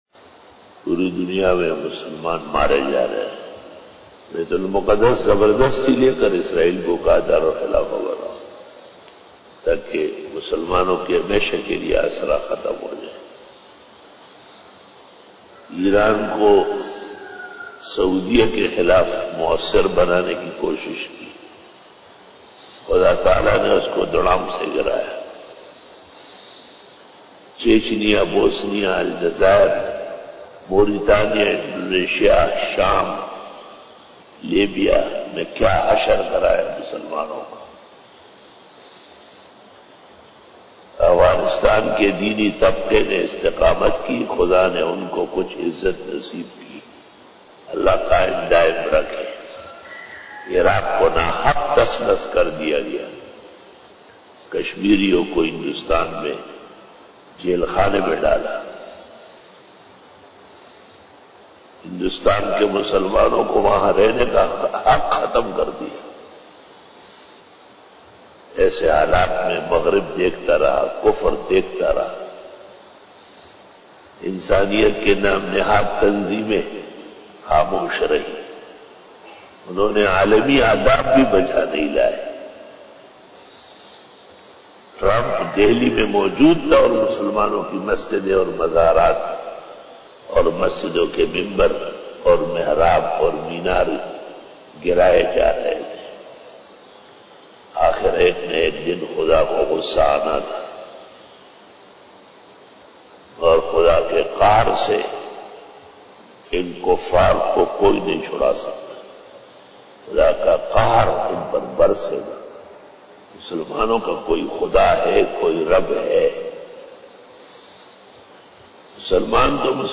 After Fajar Byan
بیان بعد نماز فجر